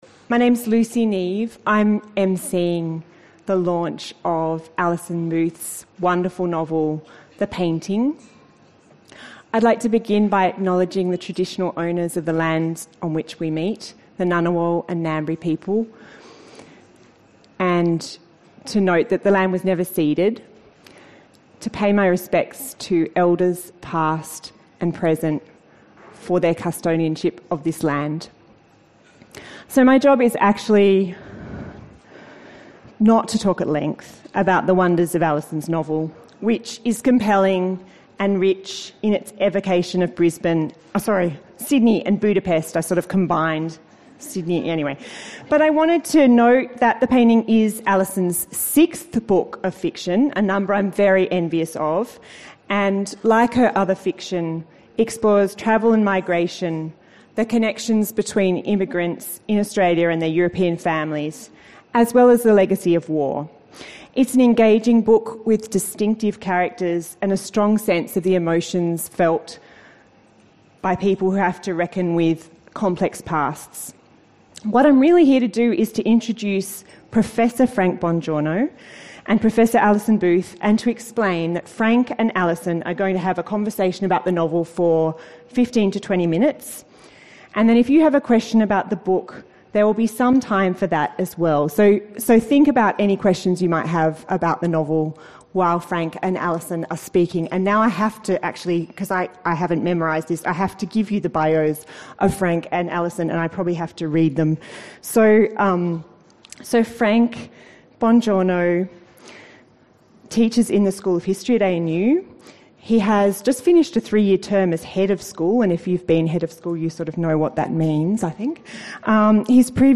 Two weeks later, on 29 July, there was a live event at The Street foyer in Canberra.